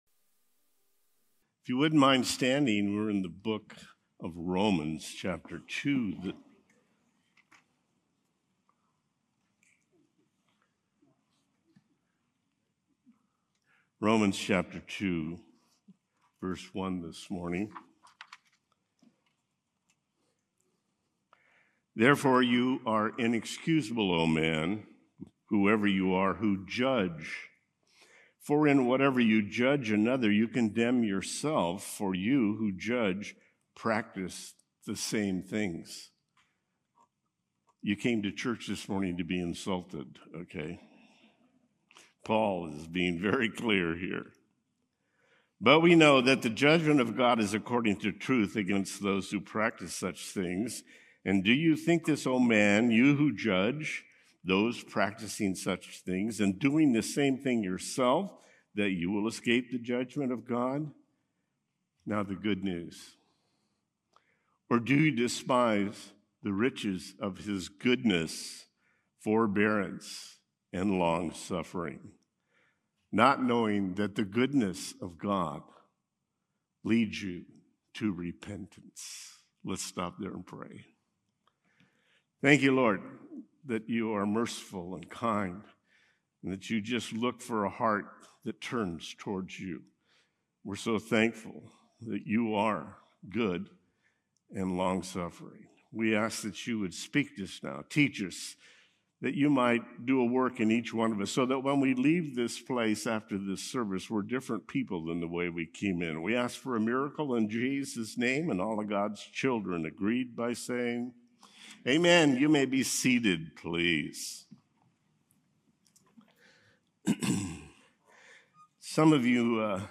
Outline 1) Hypocritical Judgement: verses 1-4 2) No Partiality: verses 5-11 3) Guilt With or Without Law: verses 12-16 Packinghouse’s Sunday morning worship service from March 2, 2025.